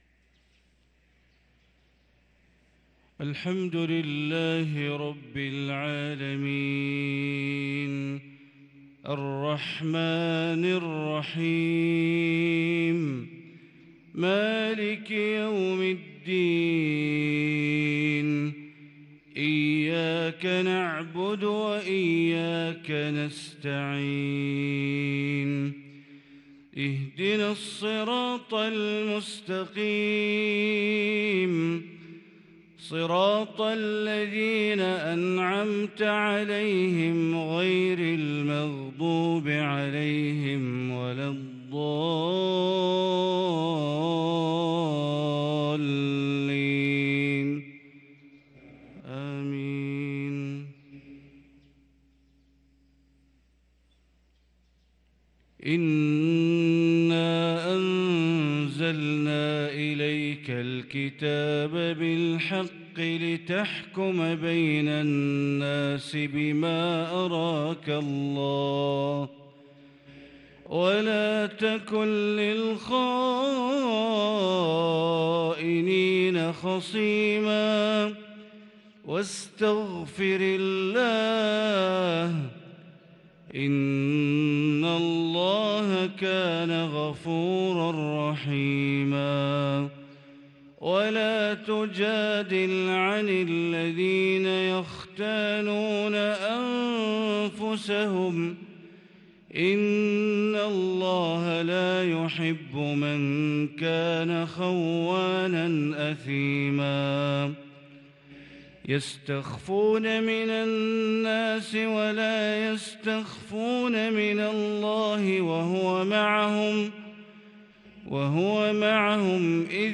صلاة الفجر للقارئ بندر بليلة 13 جمادي الأول 1444 هـ
تِلَاوَات الْحَرَمَيْن .